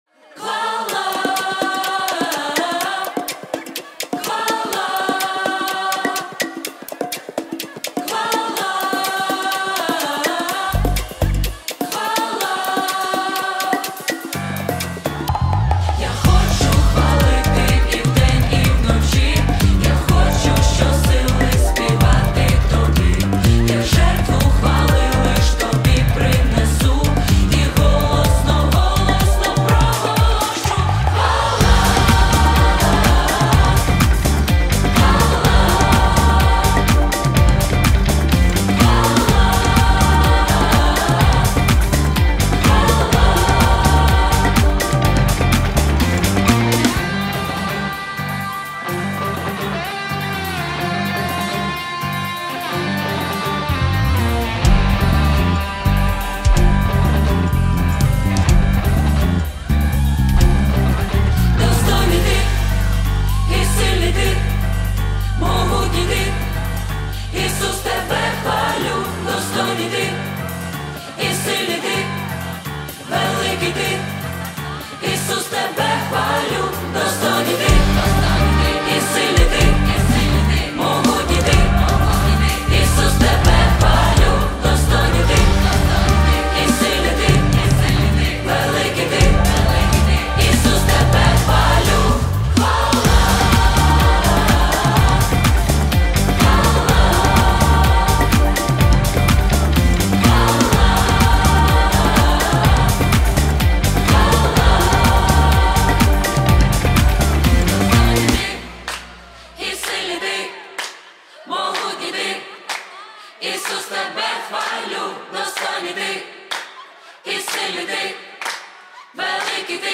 452 просмотра 681 прослушиваний 71 скачиваний BPM: 125